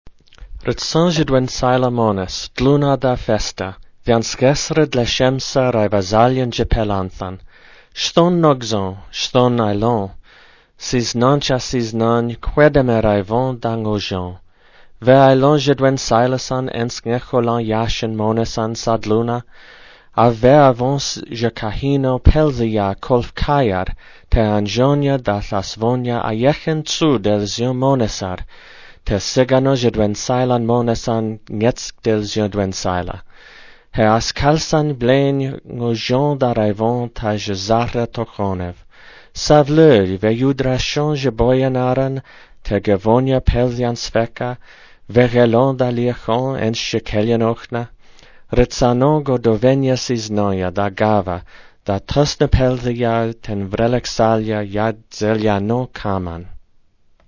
Dwensìlá Mónes - The Lonely Mountain (a native poem)